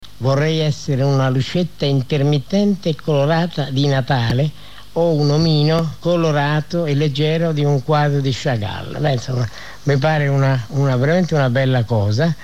Il file audio, contenente un aforisma di Stefano Mantovani, è letto da Maurizio Costanzo, grande giornalista e autore, oltre che conduttore televisivo e radiofonico a “L’uomo della notte”, programma su Radio 1 Rai degli anni ’70, ripreso nel 2007.